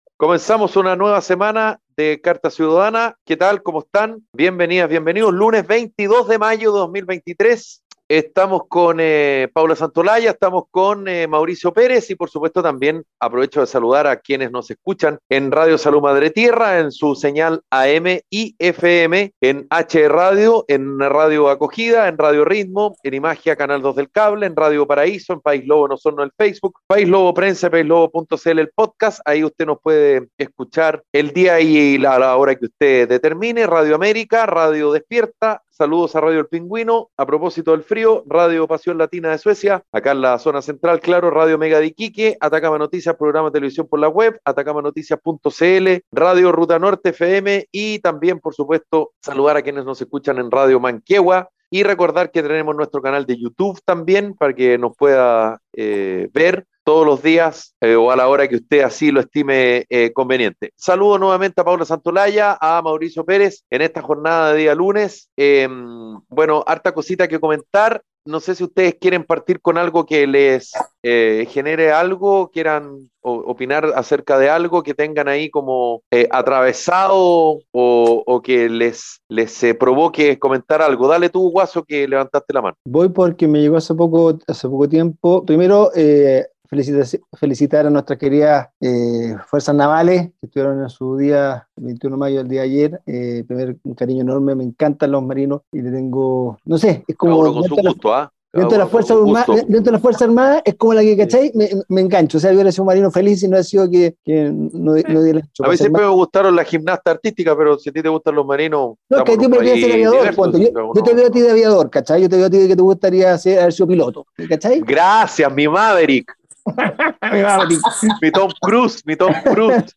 🎙 Carta Ciudadana es un programa radial de conversación y análisis sobre la actualidad nacional e internacional